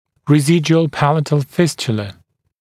[rɪ’zɪdjuəl ‘pælətl ‘fɪstjələ] [-ʧə-][ри’зидйуэл ‘пэлэтл ‘фистйэлэ] [-чэ-]остаточная нёбная фистула